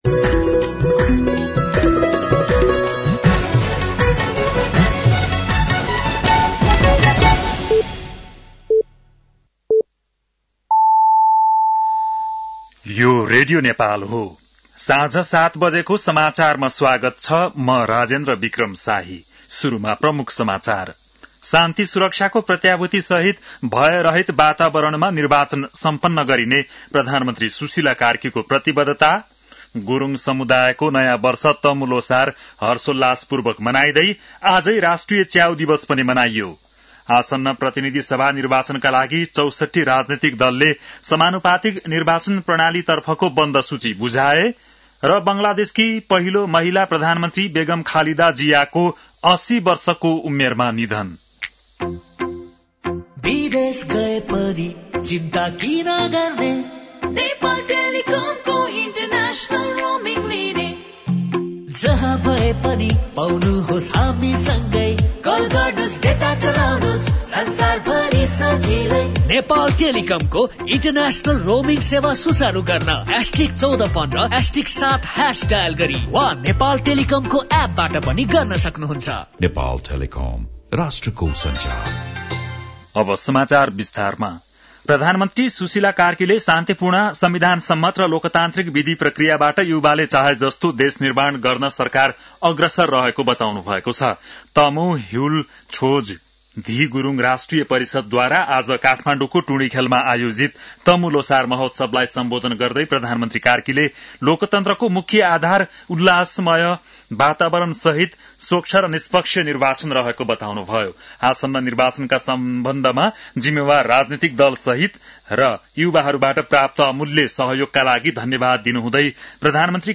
बेलुकी ७ बजेको नेपाली समाचार : १५ पुष , २०८२